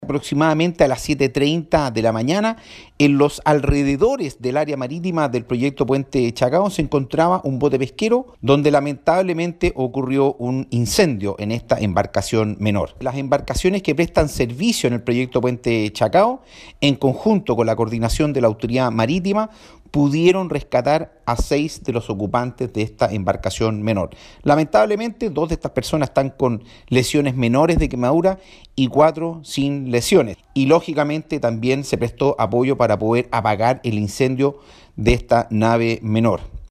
El seremi de Obras Públicas James Frey expresó que hubo intervención de embarcaciones del propio consorcio en el auxilio a los tripulantes afectados.